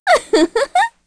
Shamilla-Vox-Laugh_kr.wav